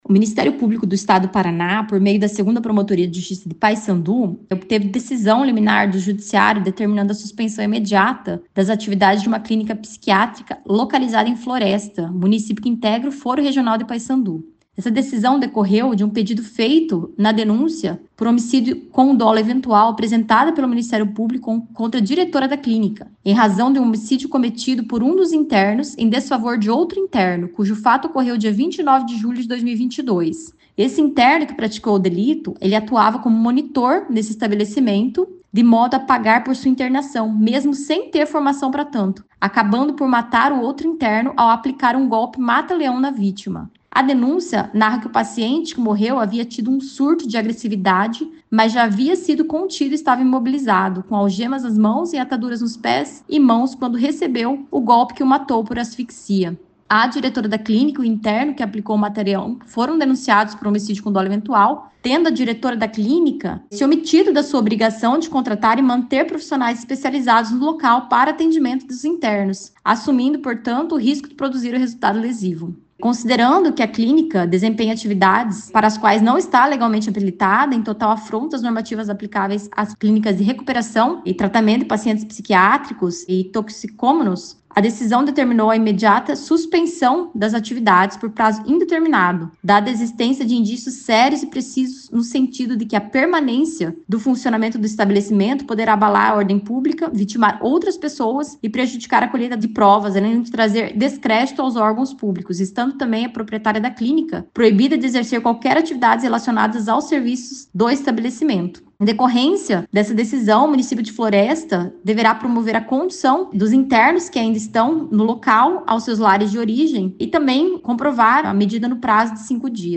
Ouça o que disse a promotora Vivian Christiane Santos Klock.